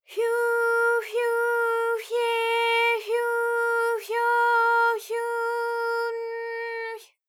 ALYS-DB-001-JPN - First Japanese UTAU vocal library of ALYS.
fyu_fyu_fye_fyu_fyo_fyu_n_fy.wav